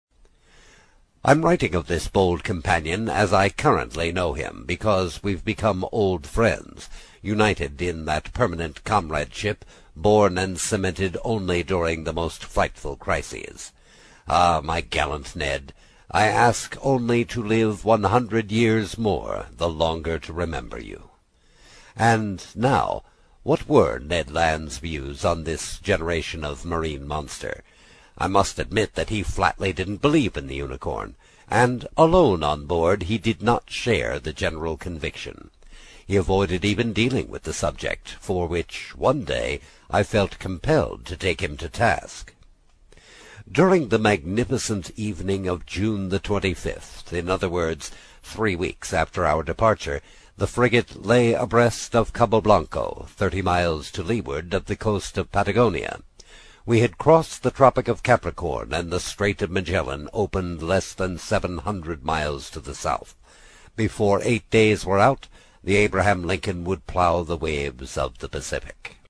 在线英语听力室英语听书《海底两万里》第37期 第4章 尼德兰(6)的听力文件下载,《海底两万里》中英双语有声读物附MP3下载